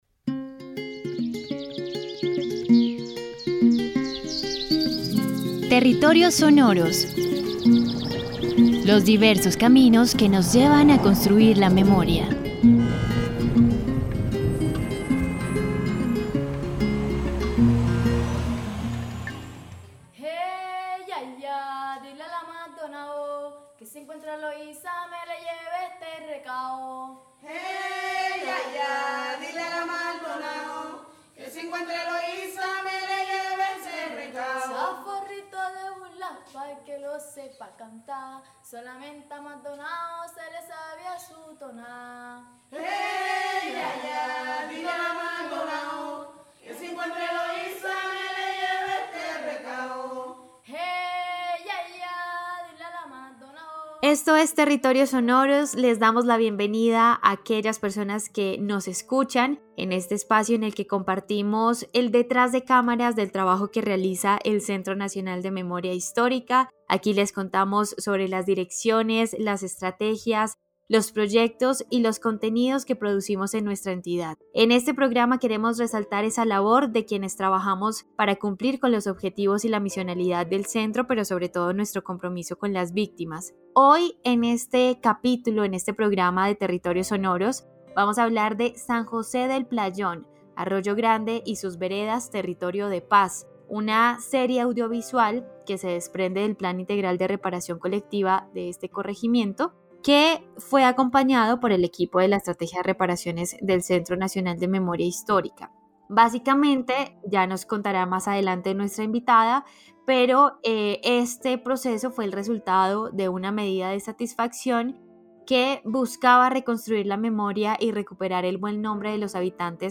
En Territorios Sonoros hablamos con el equipo de Reparaciones que acompañó este trabajo.